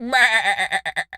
sheep_baa_bleat_high_05.wav